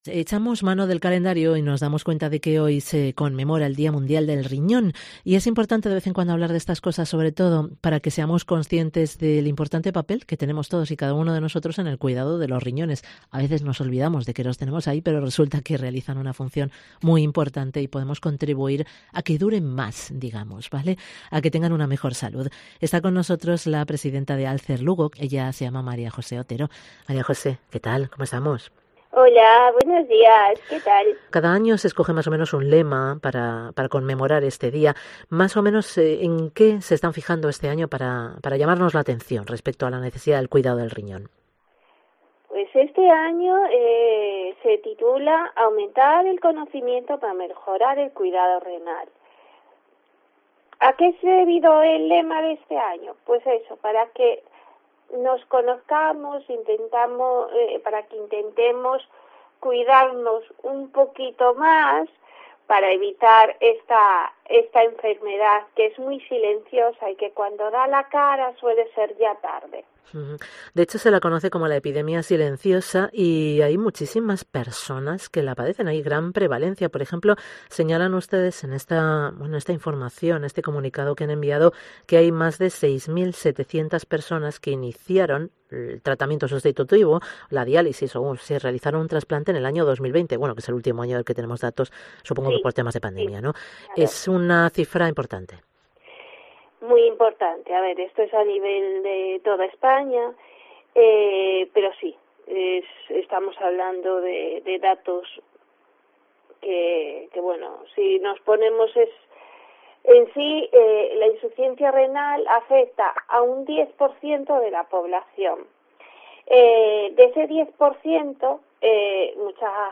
COPE de la Costa - Ribadeo - Foz Entrevista